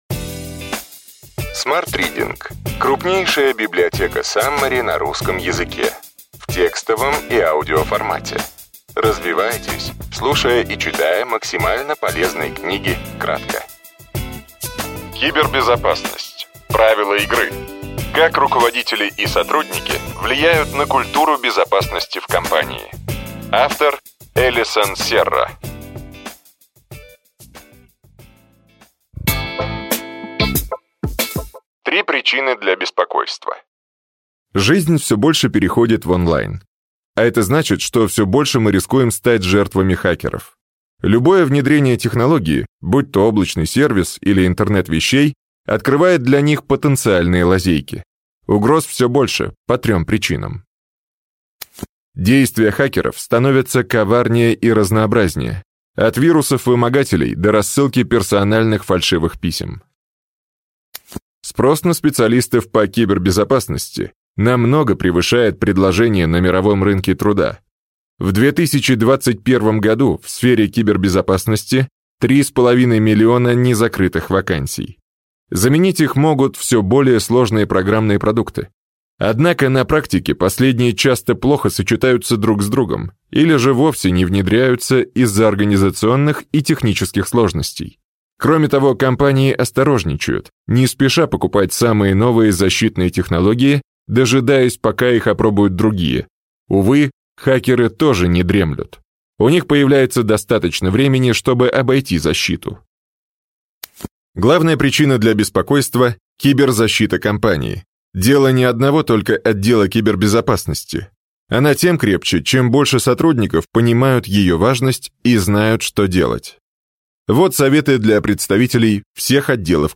Аудиокнига Ключевые идеи книги: Кибербезопасность: правила игры. Как руководители и сотрудники влияют на культуру безопасности в компании. Эллисон Серра | Библиотека аудиокниг